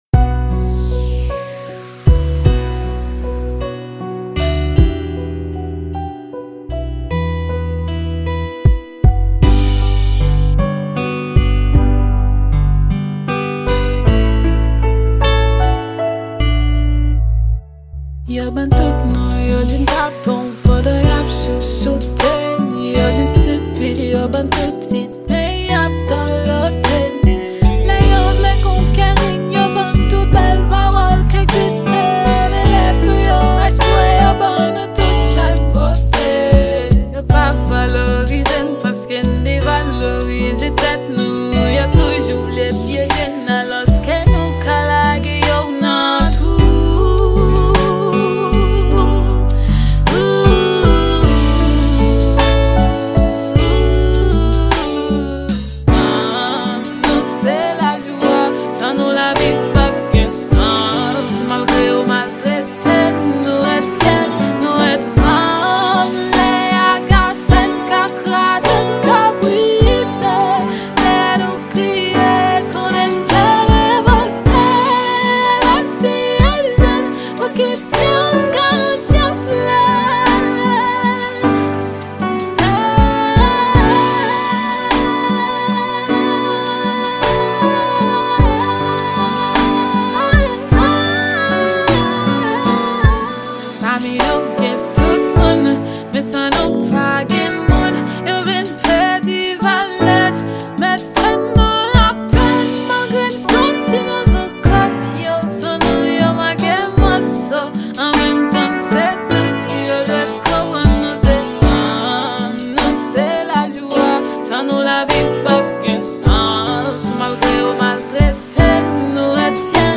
Genre: rap.